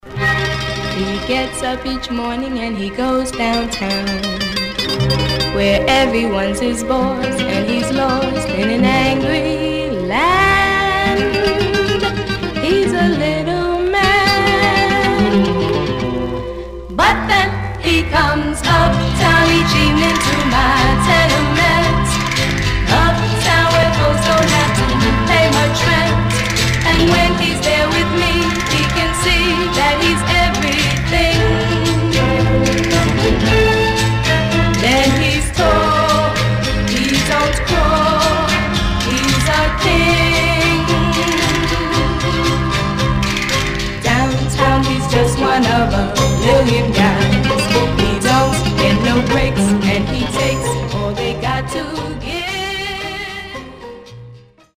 Some surface noise/wear Stereo/mono Mono
White Teen Girl Groups